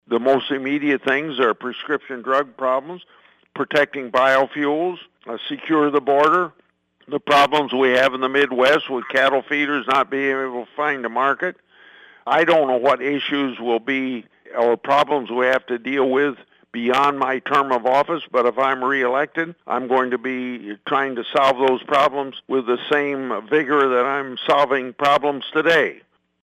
Grassley made his comments during a conference call with reporters Monday.